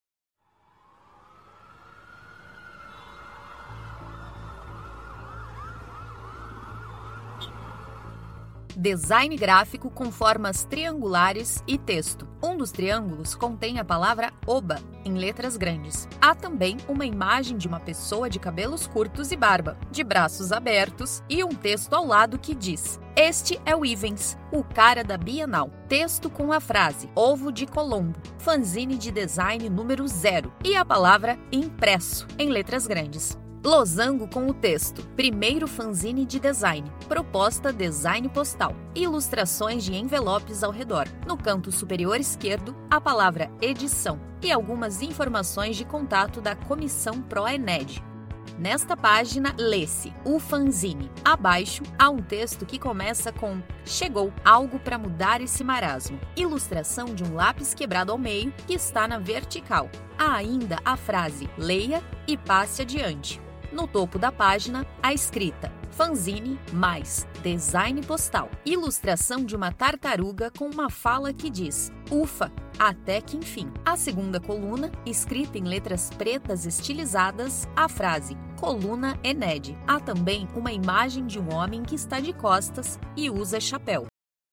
Audiodescrição do Fanzine n° 0